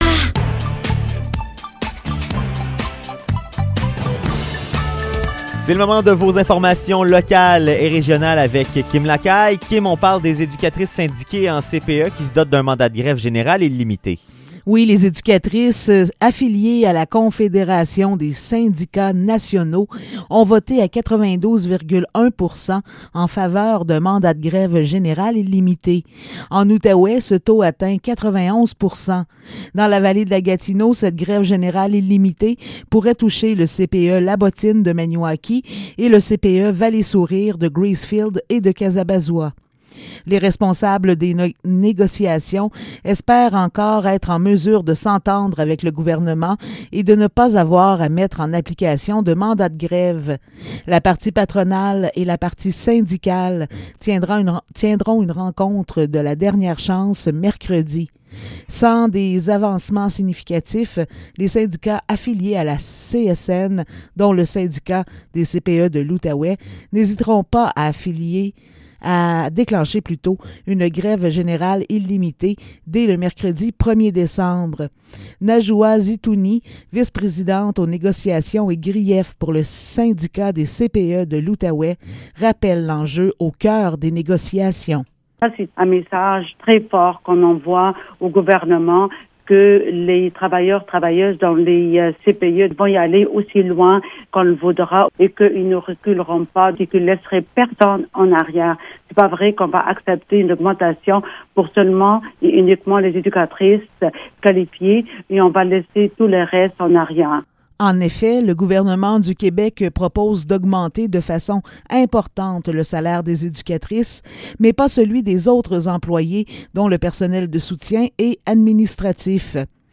Nouvelles locales - 26 novembre 2021 - 16 h